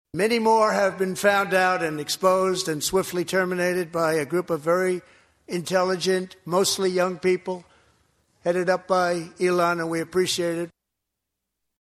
President Donald Trump spoke for over 90 minutes Tuesday night, outlining the agenda for his current term in office before a joint session of Congress.